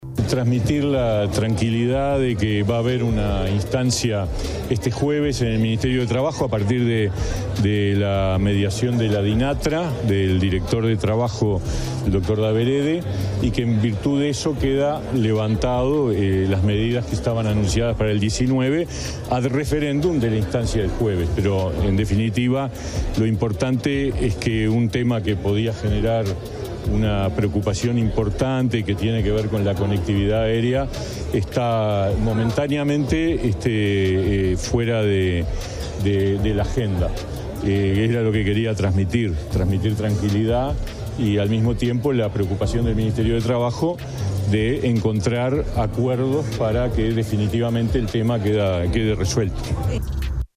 El ministro de Trabajo y Seguridad Social, Pablo Mieres, dijo en rueda de prensa que un tema que podía generar una «preocupación importante», esta momentáneamente «fuera de la agenda».
Escuchá las declaraciones del ministro de Trabajo, Pablo Mieres: